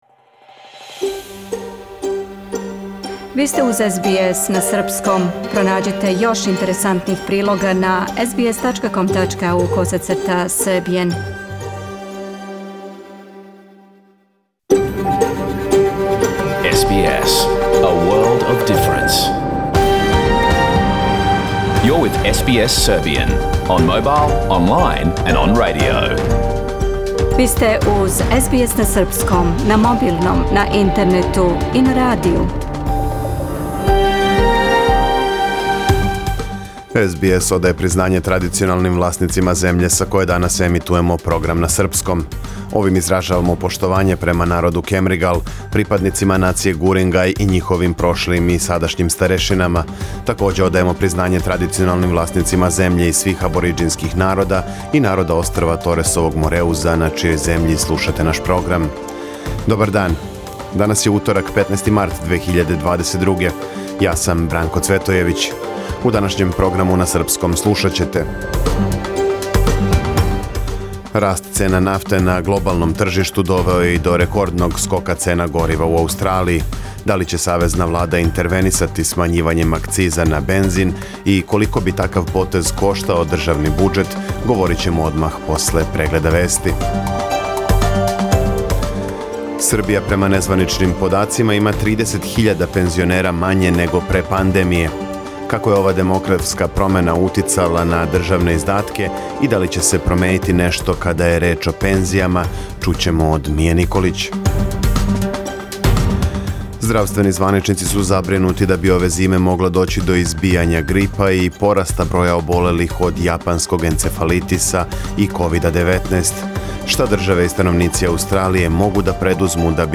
Програм емитован уживо 15. марта 2022. године
Ако сте пропустили нашу емисију, сада можете да је слушате у целини као подкаст, без реклама.